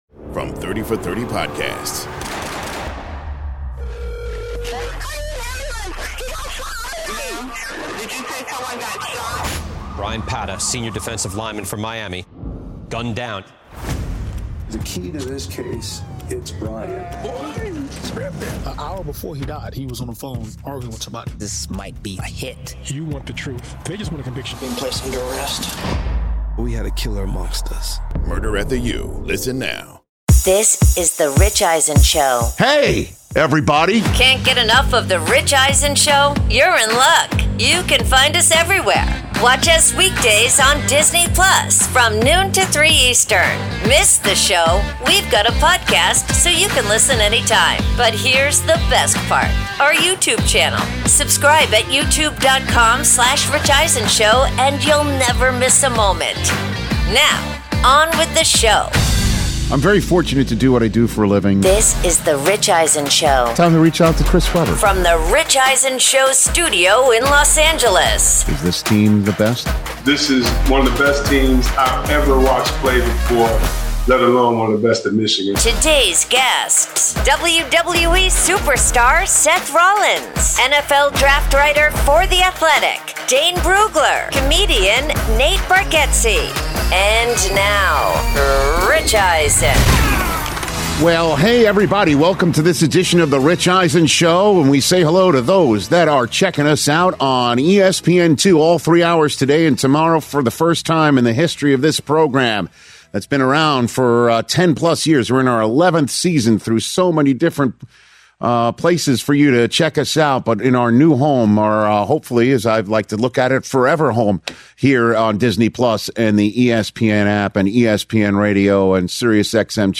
Hour 1: Rich Reveals He’ll be Hosting ‘SportsCenter’ from Bristol, plus WWE Superstar Seth Rollins In-Studio Podcast with Rich Eisen